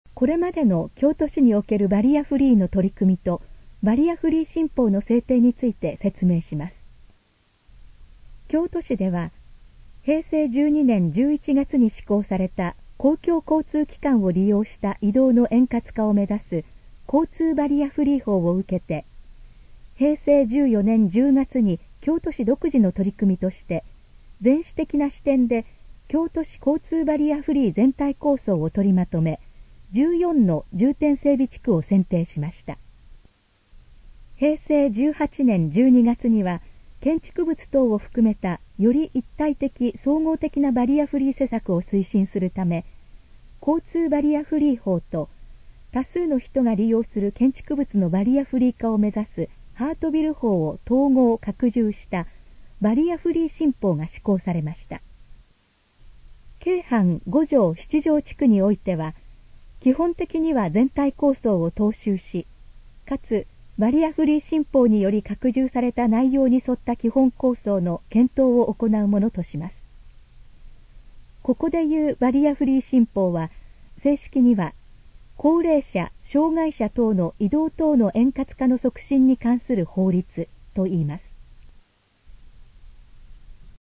このページの要約を音声で読み上げます。
ナレーション再生 約328KB